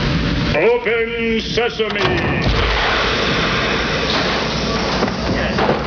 Data saying "Open sesame" (64KB) - wav